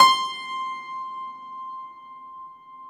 53a-pno18-C4.wav